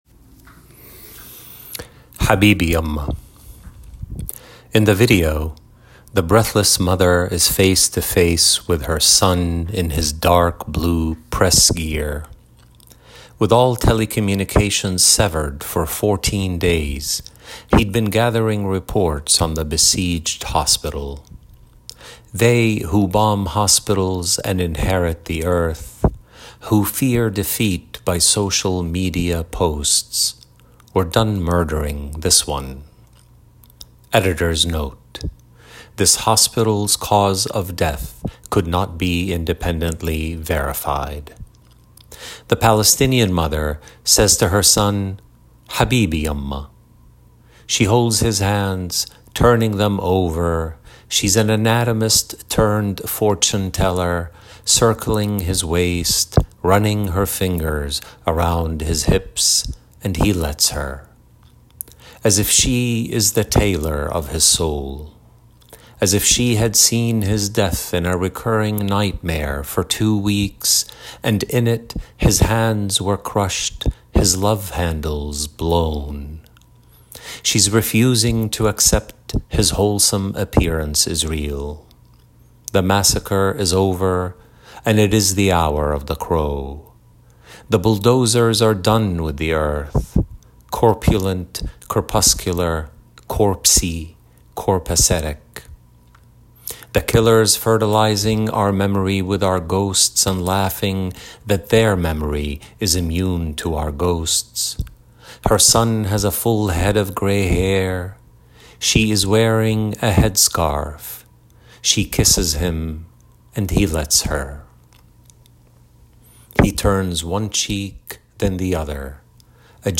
Listen to Fady Joudah read “Habibi Yamma”
Fady-Joudah-reading-22Habibi-Yamma22.m4a